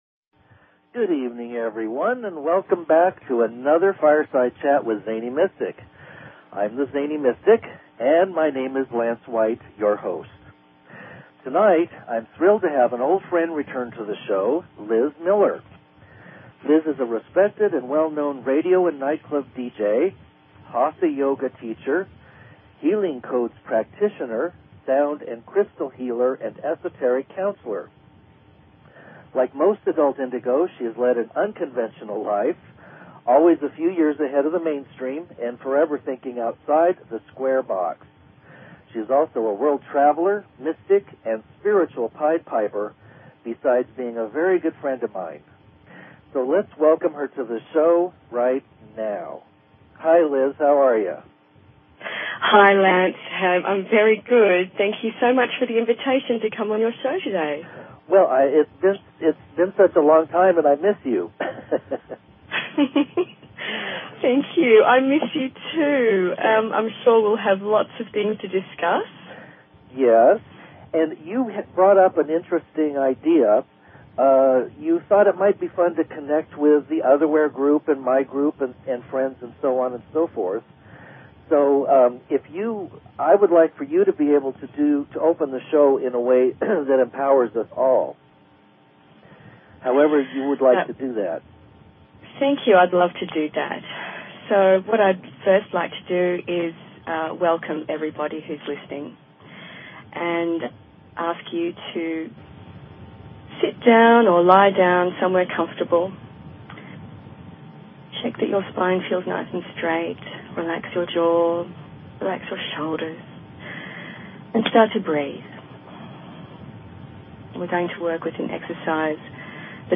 Talk Show Episode
She led the groups in a guided meditation, which allowed all of us to connect and experience our highest selves. We spoke about the present energies, coming shifts, 2012, and what mysteries exist around the bend...